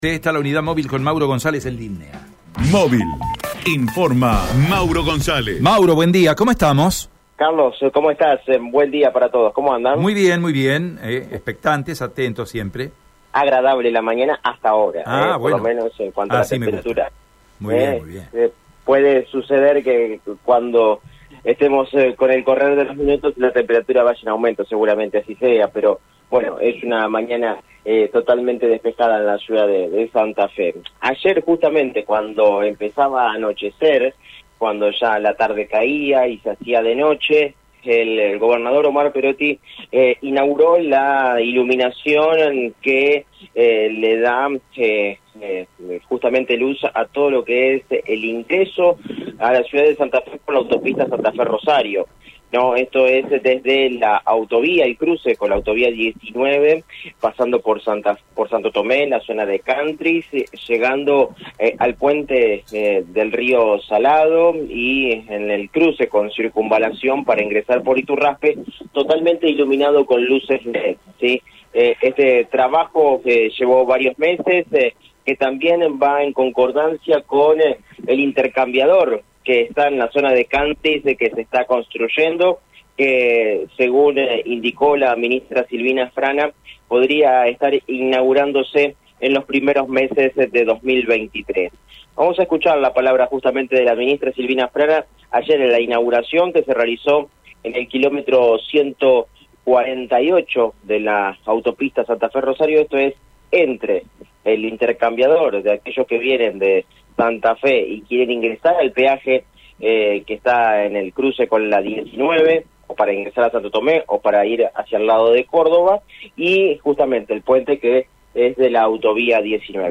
Escuchá la palabra de Silvina Frana: